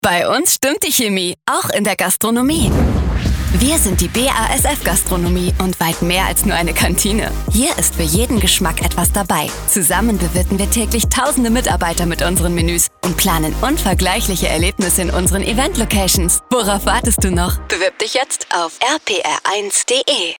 Spots auf den Webradio-Channels von bigFM und RPR1.